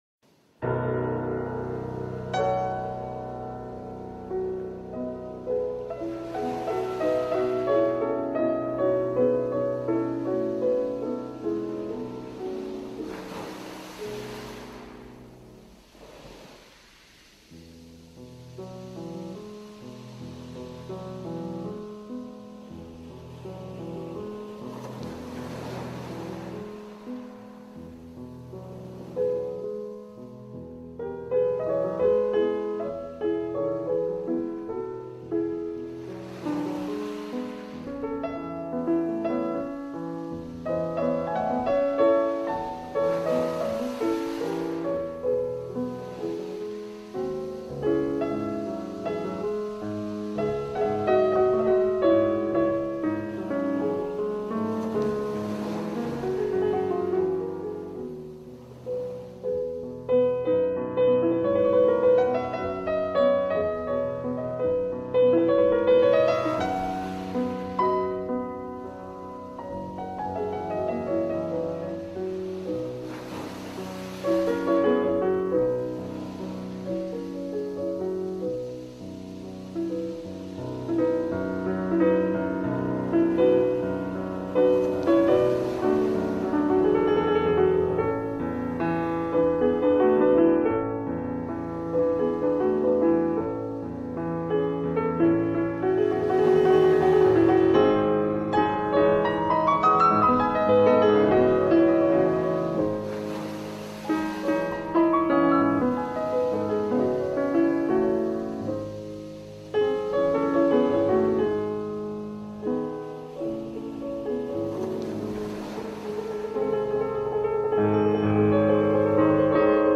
Chopin – Deep Reading Flow with Gentle Piano Sound